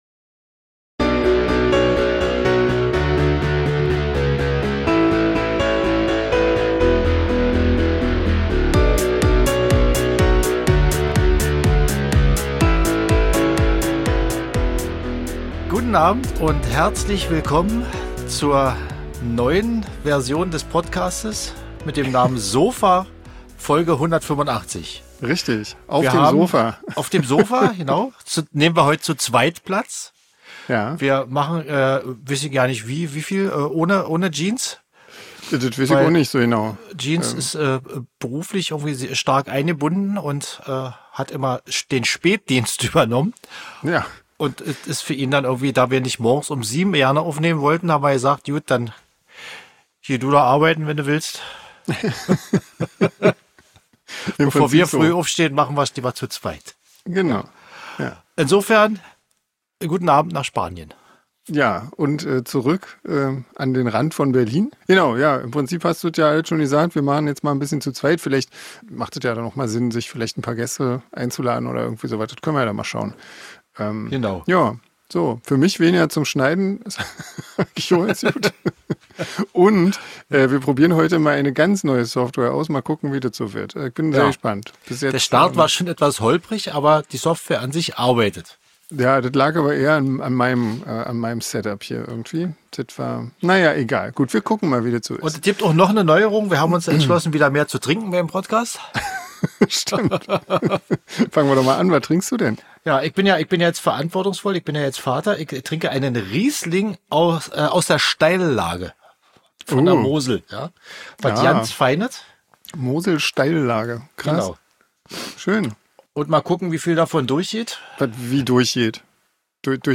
Stellt Fragen an die Bandmitglieder, schlagt ihnen Themen vor, über die sie reden sollen oder freut Euch einfach über die Gespräche der 3 Musiker.